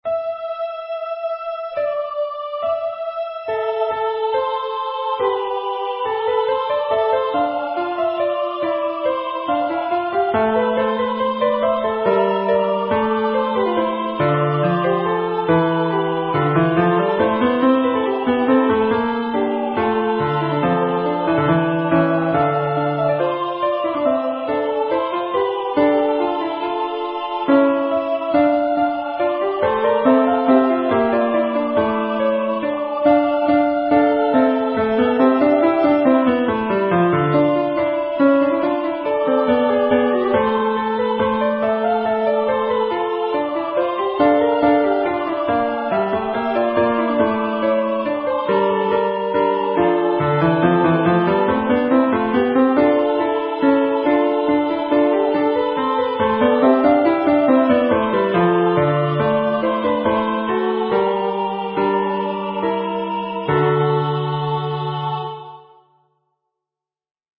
MP3 Practice Files: Soprano:   Alto:   Bass:
Number of voices: 3vv   Voicing: SAB
Genre: SacredMotet
Instruments: Piano
ResurrexiBassP.mp3